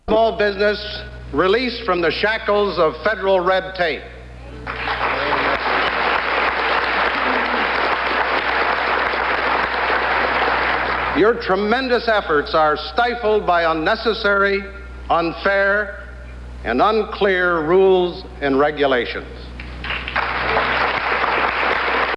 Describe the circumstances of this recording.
Broadcast on CBS-TV, June 17, 1975.